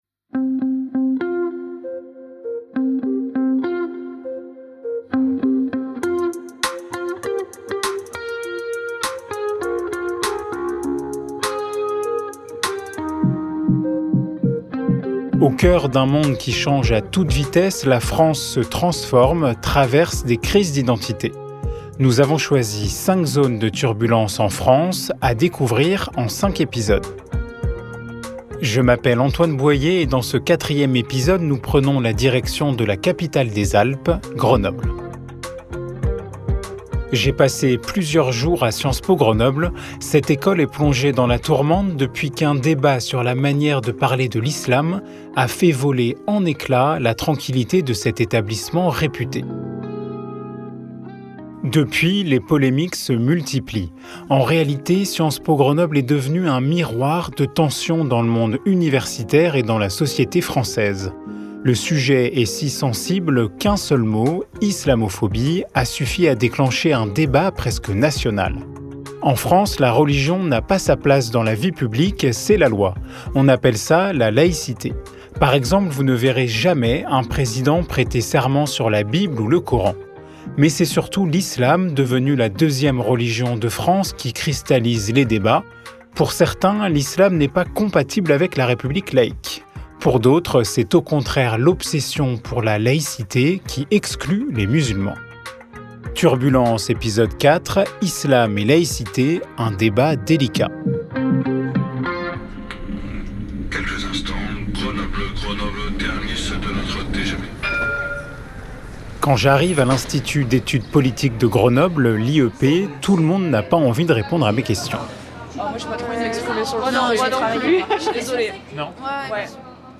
Dans cet épisode, nous partons dans la capitale des Alpes, Grenoble, à l’Institut d’Etudes Politiques, plongé dans la tourmente après qu’un débat interne sur la manière de parler de l’islam a été connu, suscitant de multiples réactions dans la classe politique.